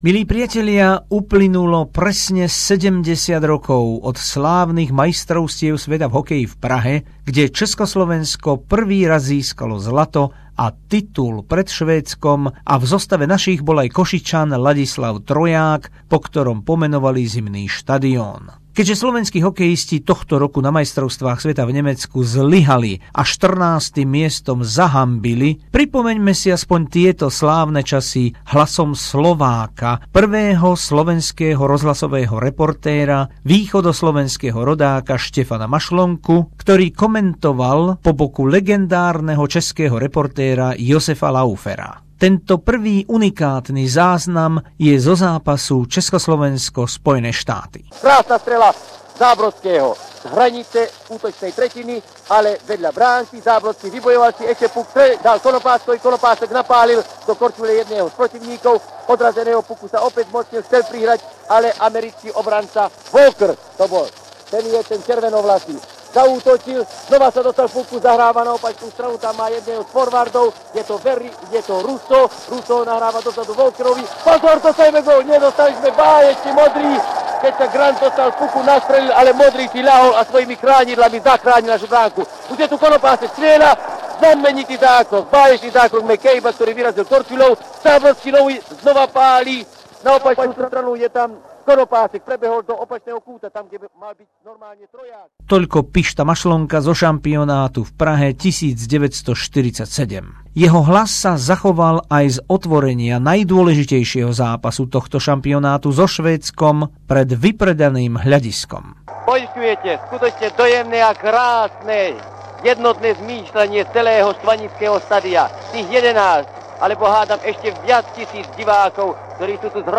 Unikátne zvukové pripomenutie legendárnych slovenských rozhlasových a televíznych komentátorov Štefana Mašlonku a Ruda Galla zo zlatej éry československého hokeja. Prešlo 70 rokov od prvého hokejového titulu majstrov sveta na pražskej Štvanici.